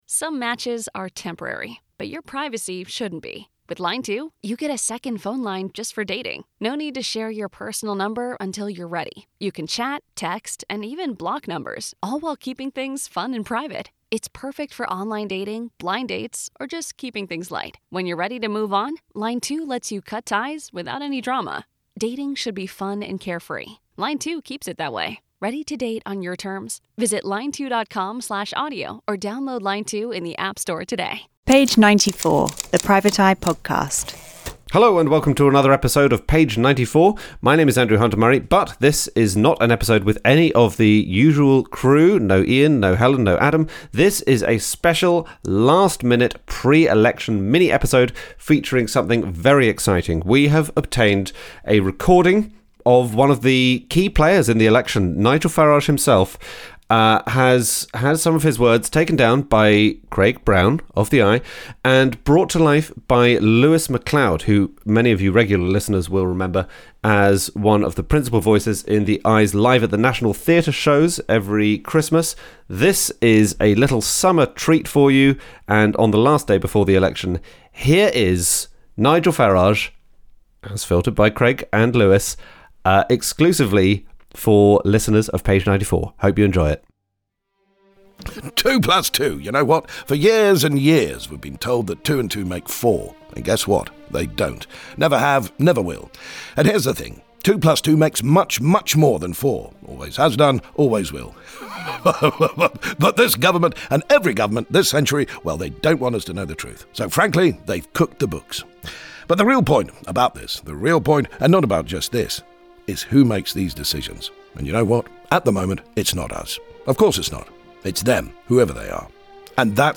A special mini-episode treat - the wit and wisdom of Nigel Farage (is this right? Ed), as channelled by the Eye’s Craig Brown and Lewis Macleod. Find out what Nigel REALLY thinks about good old-fashioned British maths.